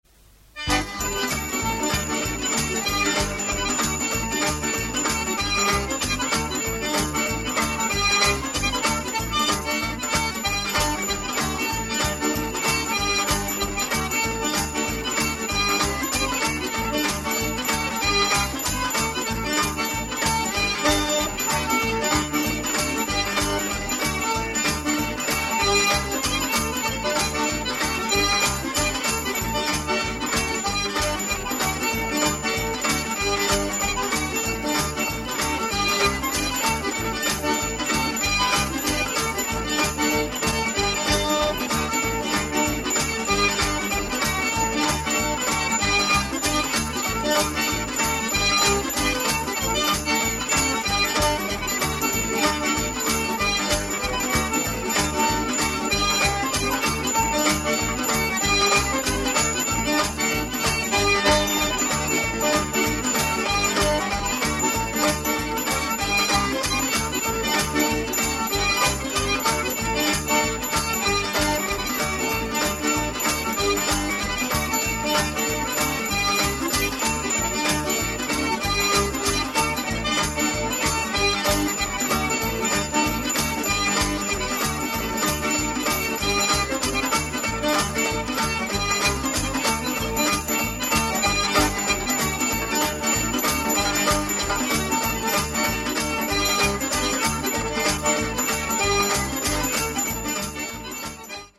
• Качество: 128, Stereo
веселые
заводные
инструментальные
национальные